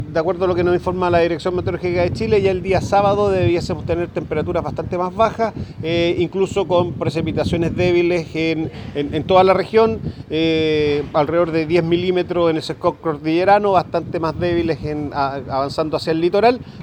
El director del Senapred en el Bío Bío, Alejandro Sandoval, informó que en los últimos días se han combatido más de 17 incendios al día, algunos de rápida extinción y otros que son más complejos de atacar. De todas maneras, dijo que las condiciones del tiempo cambiarán a favor en los próximos días.